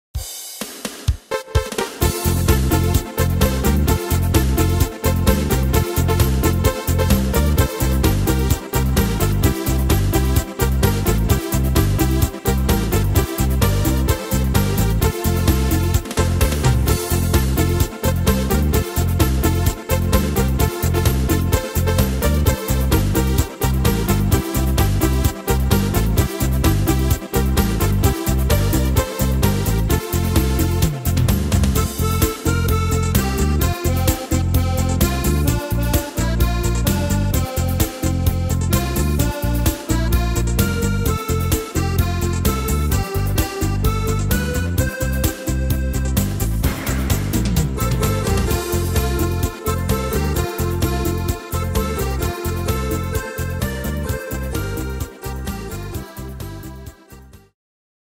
Tempo: 129 / Tonart: F-Dur